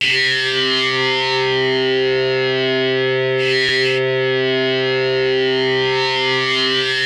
TALK GUITAR 1.wav